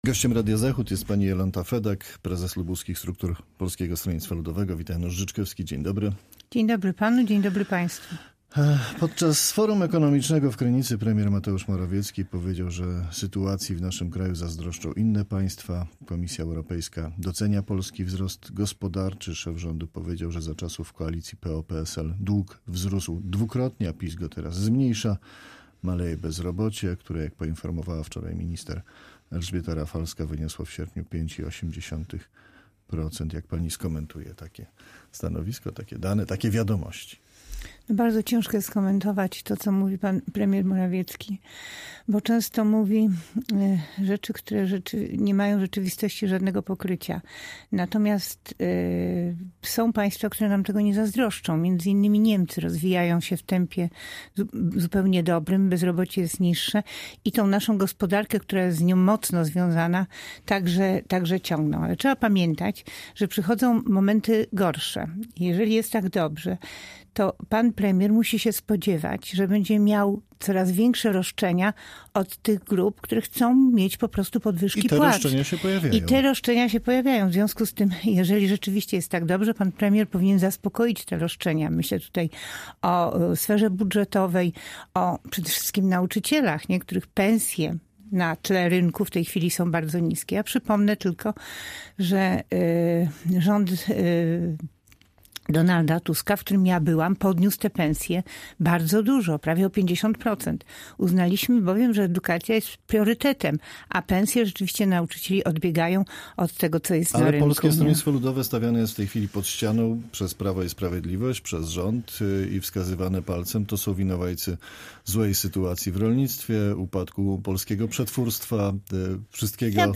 Z liderką lubuskiego Polskiego Stronnictwa Ludowego rozmawia